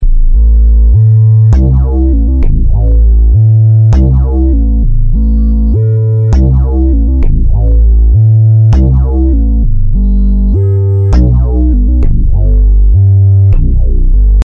mam mb33 » MB33 BASS1.2
标签： bass mam mb33
声道立体声